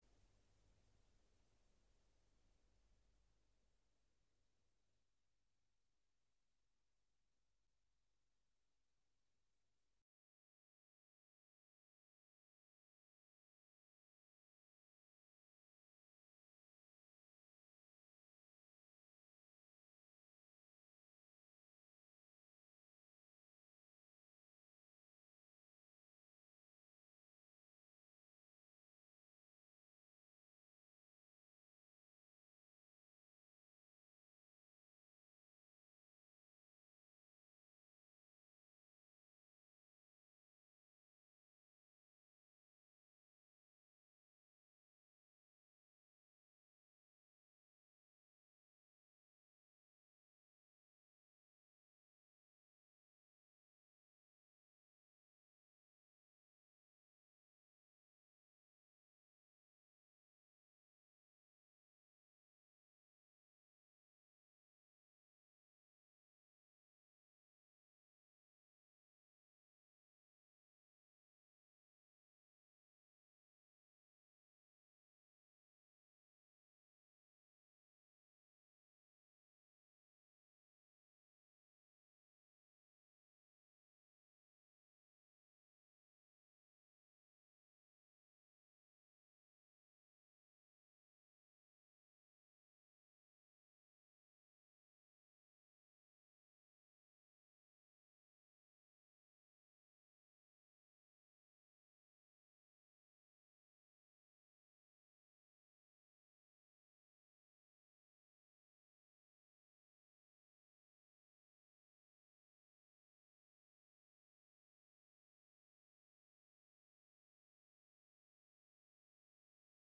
Villancico
~1400 - ~1800 (Renaissance)